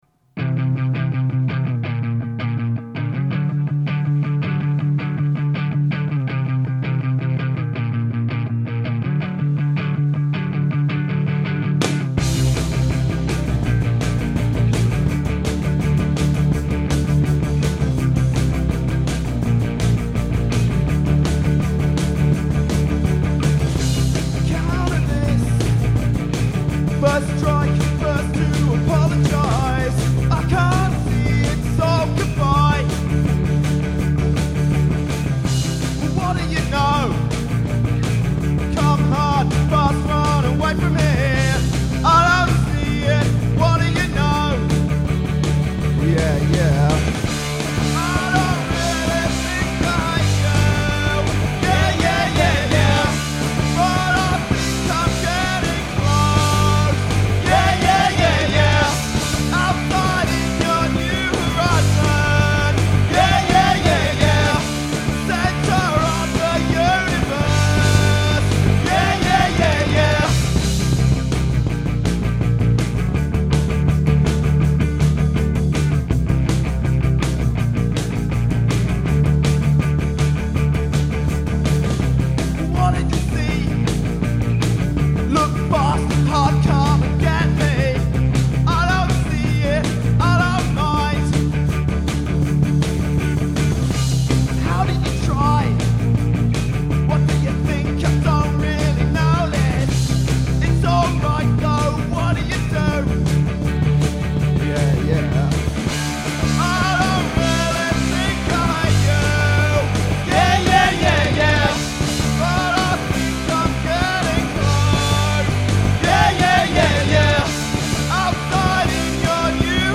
1-5 diy recordings mushroom rehearsal studios